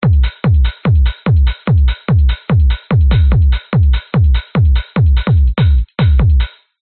描述：Drums,drum loop
Tag: 106 bpm House Loops Drum Loops 390.58 KB wav Key : Unknown